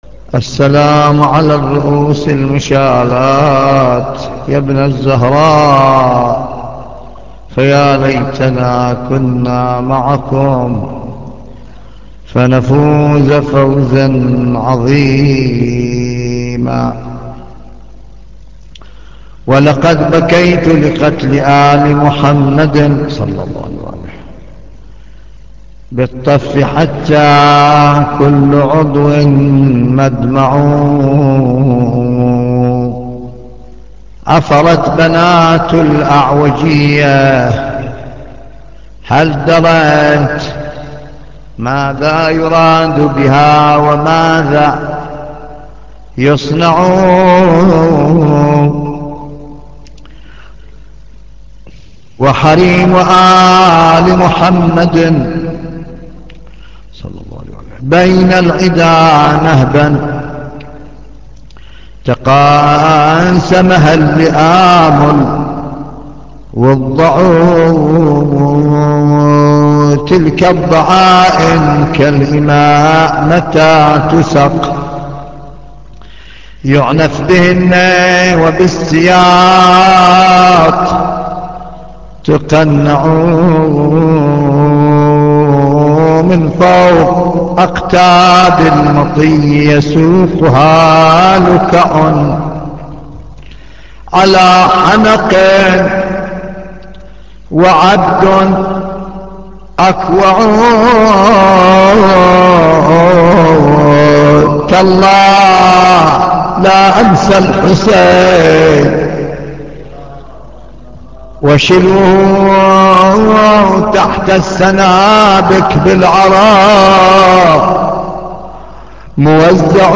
نواعـــــي